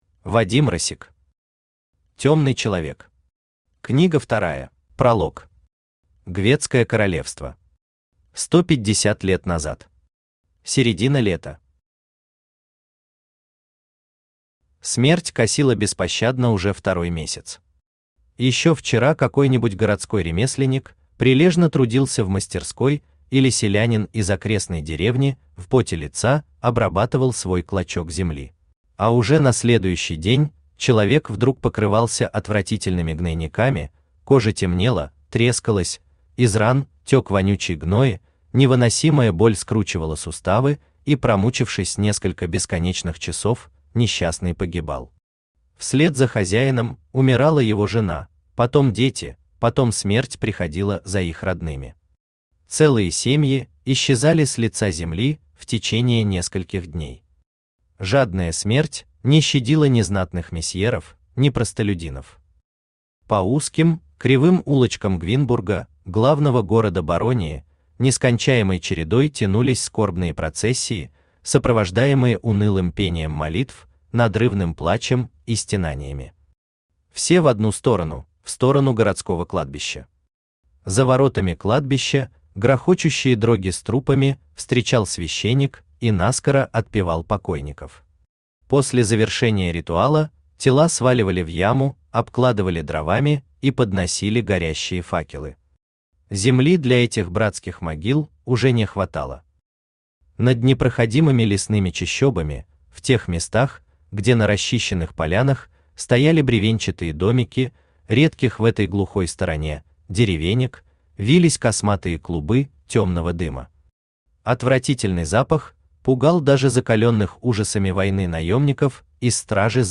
Аудиокнига Тёмный человек. Книга вторая | Библиотека аудиокниг
Книга вторая Автор Вадим Россик Читает аудиокнигу Авточтец ЛитРес.